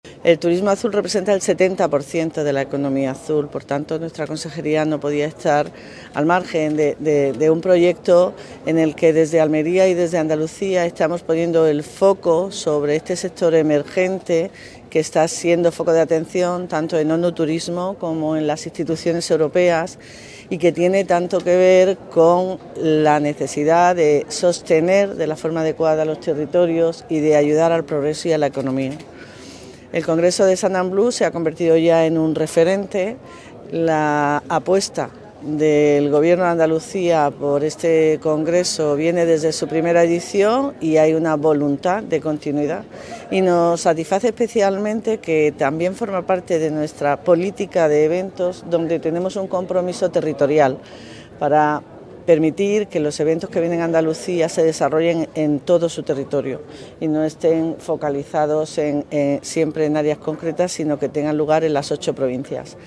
YOLANDA-DE-AGUILAR-SECRETARIA-GENERAL-TURISMO-JUNTA-INAUGURACION-SUNBLUE.mp3